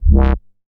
MoogVocaFilta A.WAV